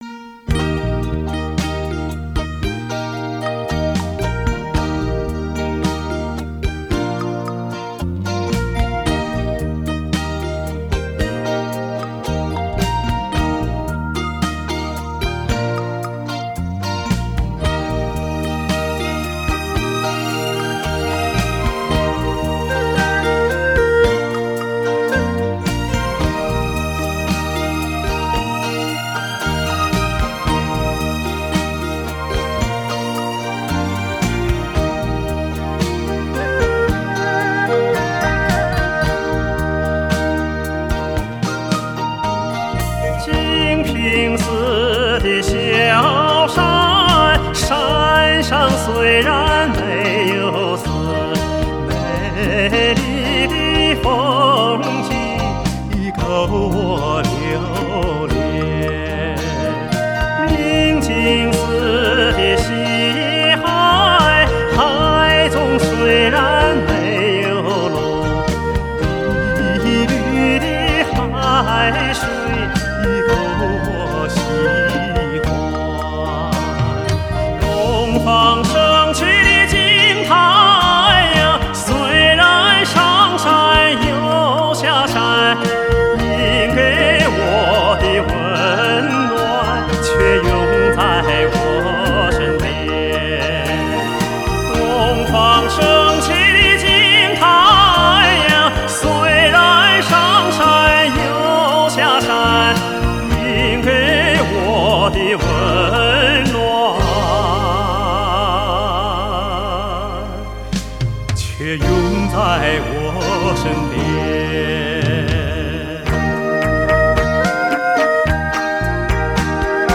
著名歌唱家合辑
自购碟原抓  WAV整轨